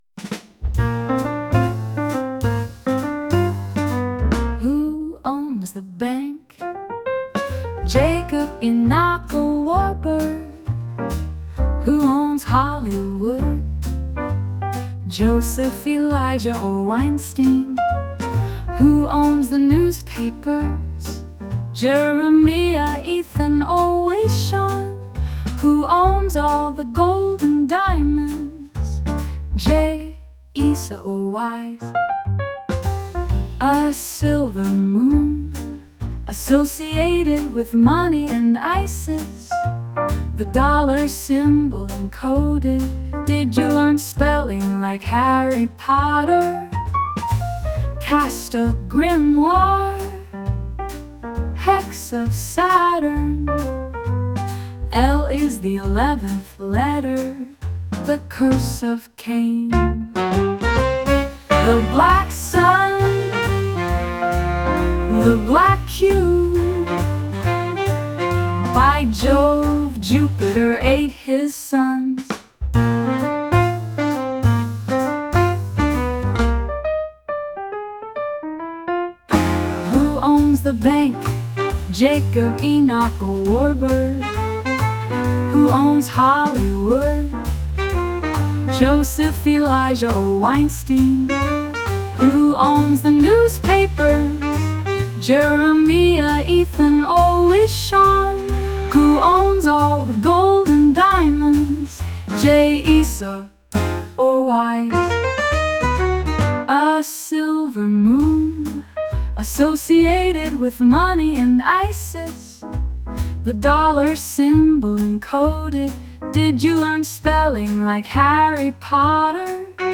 JAZZ VERSION]